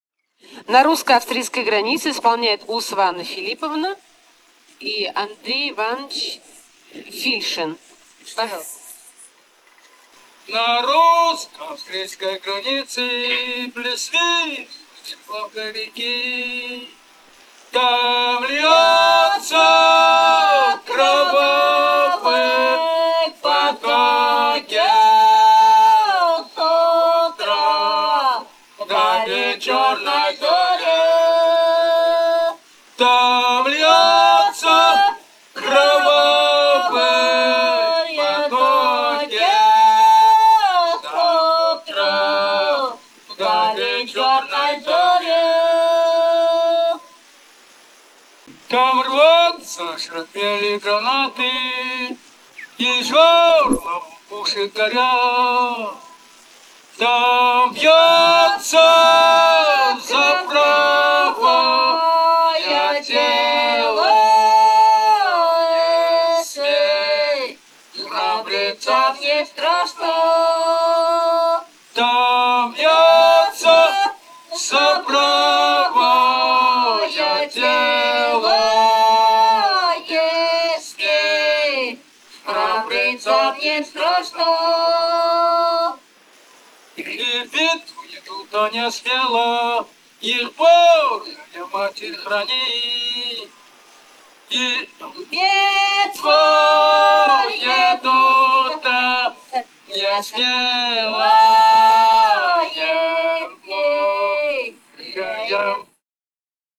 Этномузыкологические исследования и полевые материалы
Бурятия, с. Желтура Джидинского района, 1966 г. И0904-03